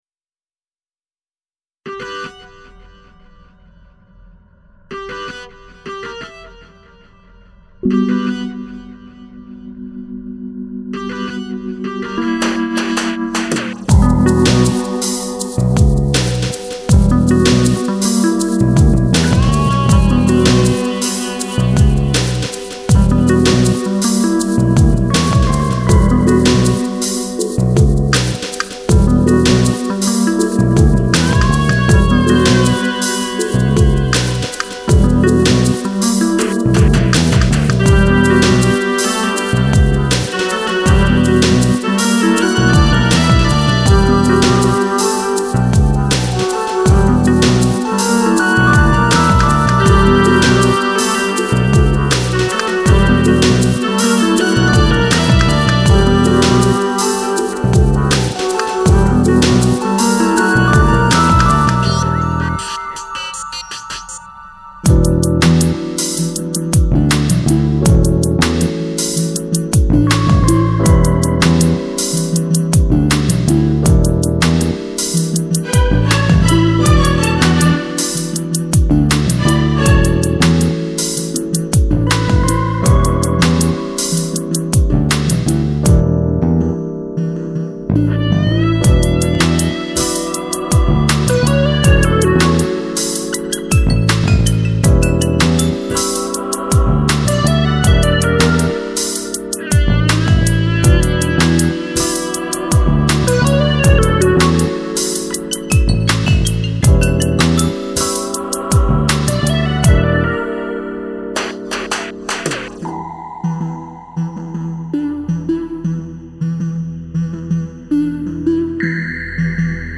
Genre: DowntempoTrip-Hop.